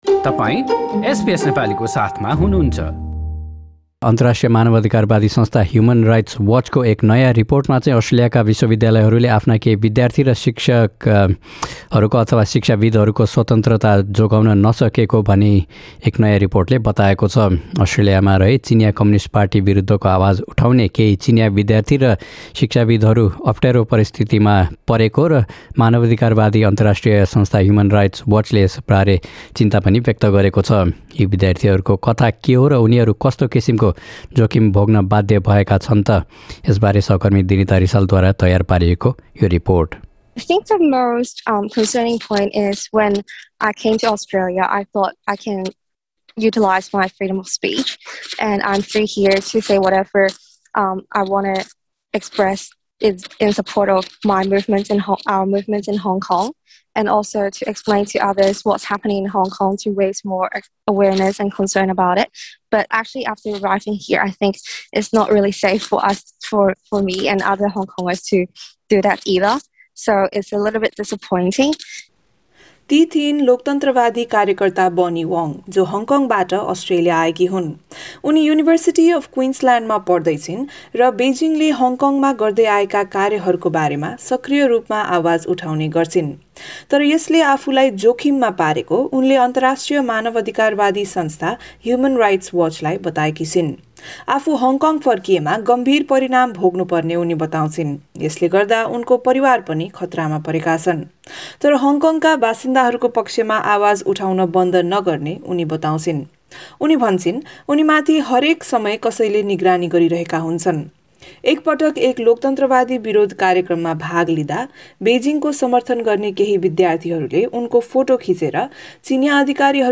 एसबीएस नेपाली पोडकास्ट